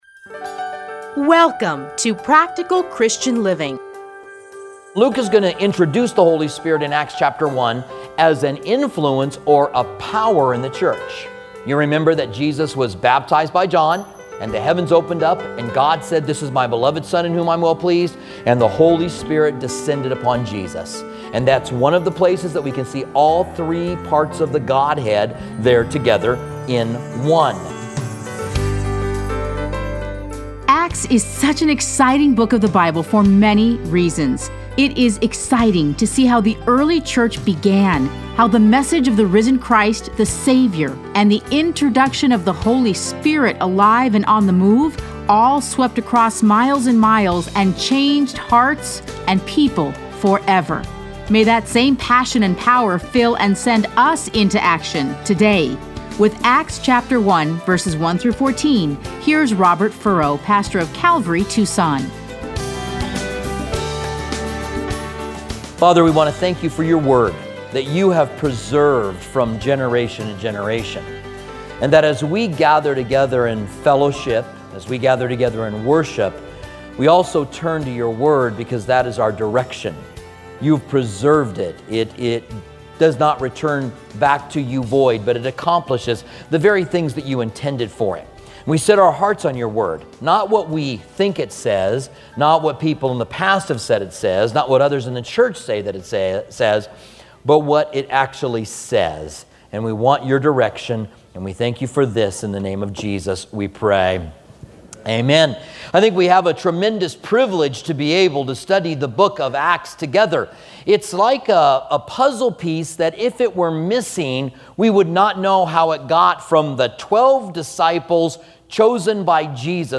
Listen to a teaching from Acts 1:1-14.